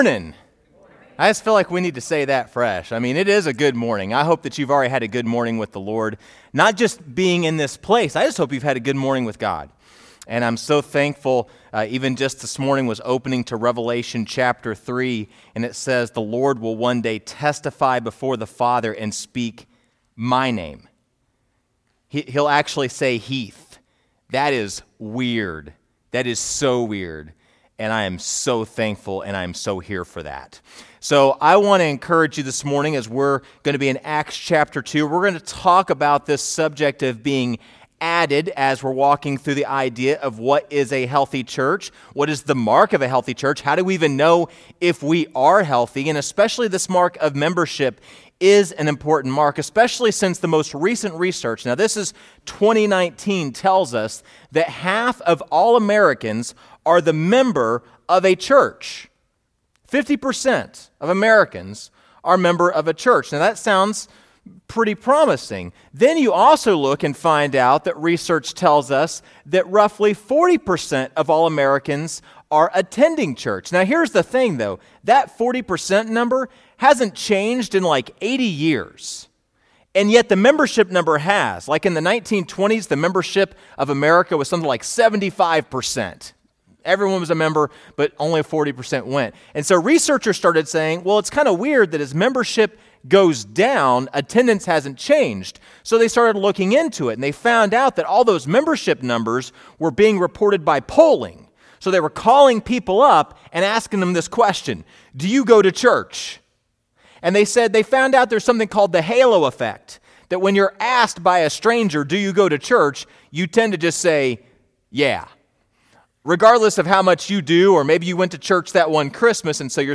First Baptist Church of Machesney Park Sermon Audio